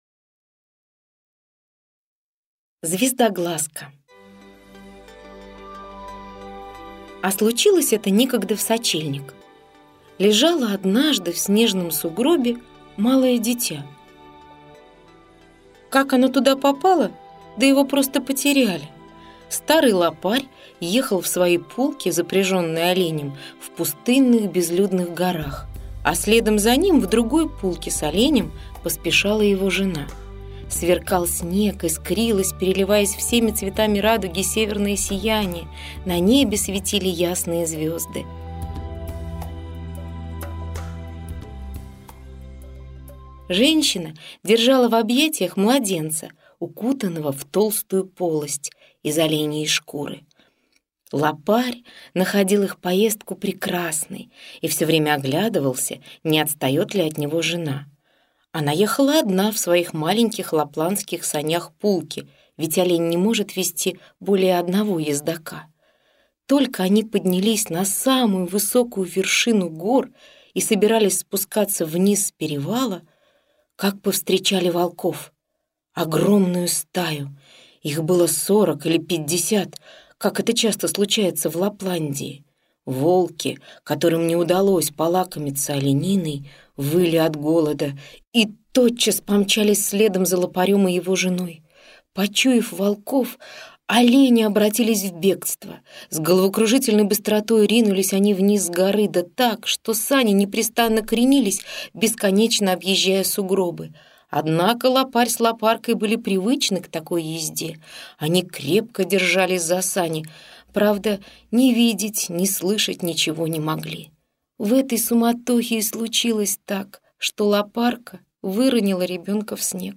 Звездоглазка - аудиосказка Сакариаса Топелиуса - слушать скачать